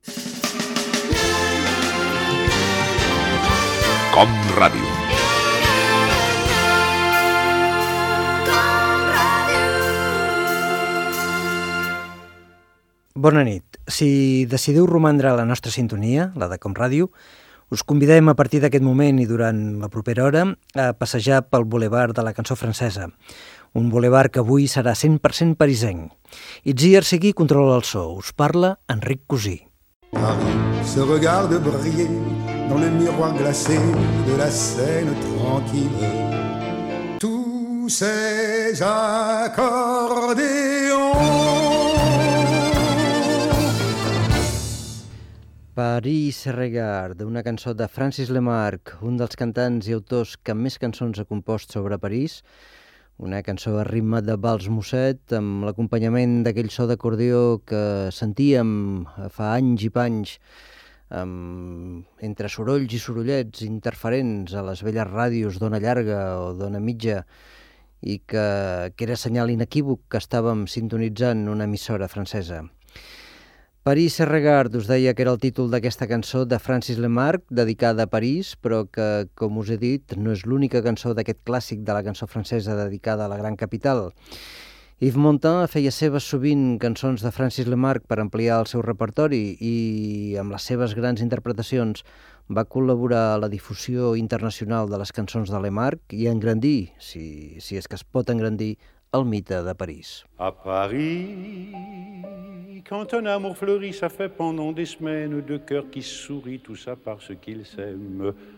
Indicatiu de l'emissora, presentació del programa dedicat a la cançó francesa i tema musical
Musical
FM